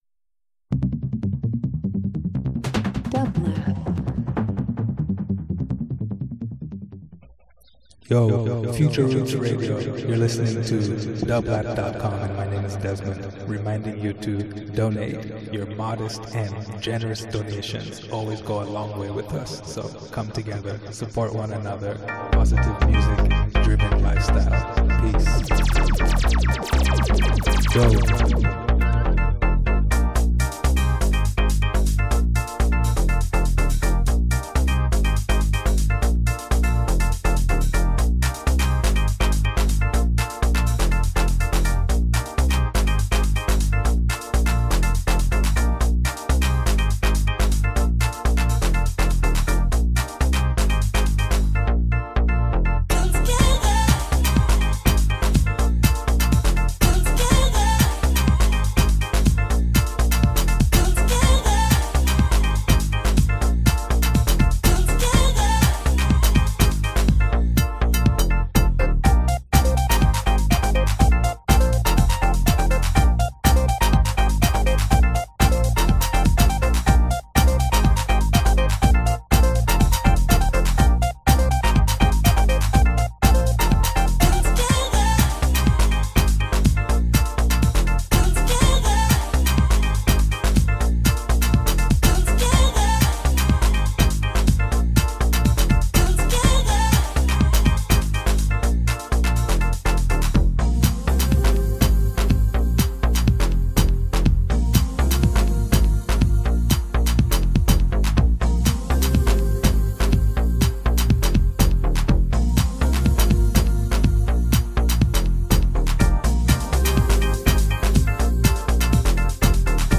Afrobeat Dance House